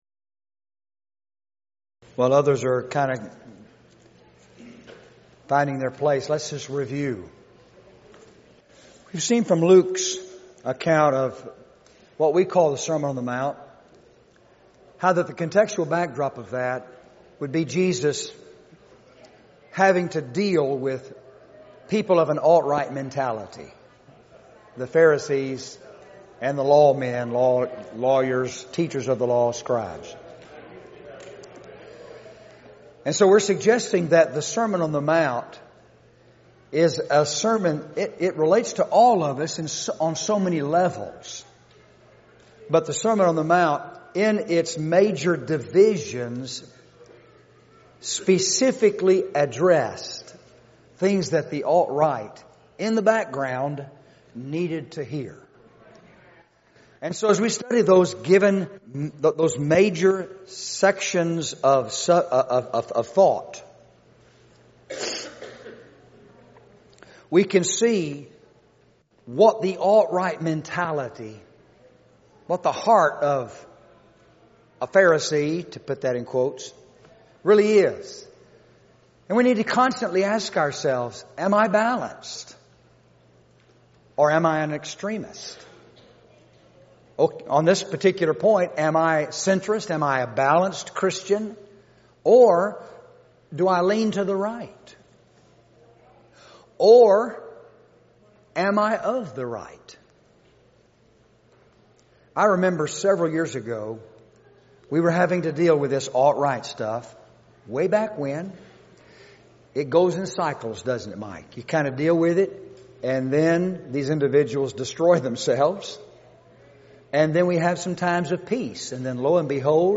Event: 2019 Focal Point Theme/Title: Preacher's Workshop
lecture